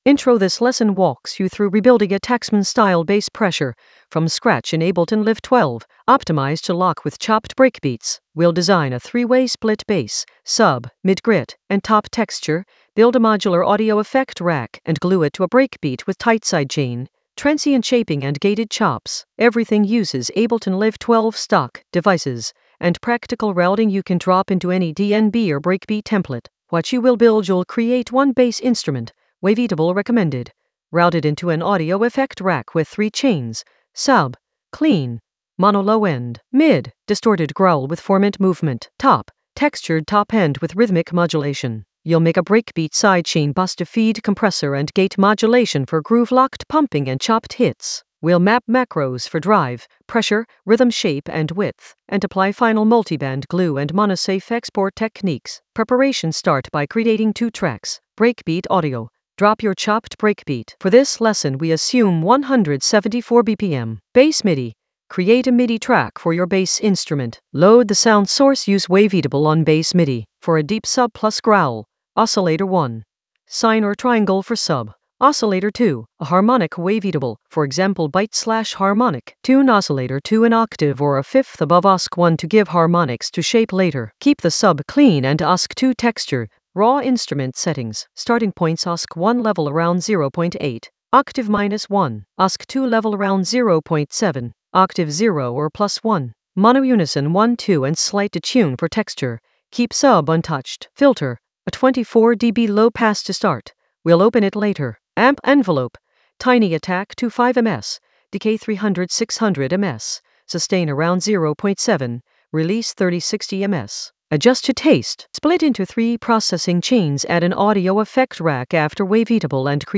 An AI-generated advanced Ableton lesson focused on Taxman edit: rebuild a bass pressure from scratch in Ableton Live 12 for breakbeat science in the FX area of drum and bass production.
Narrated lesson audio
The voice track includes the tutorial plus extra teacher commentary.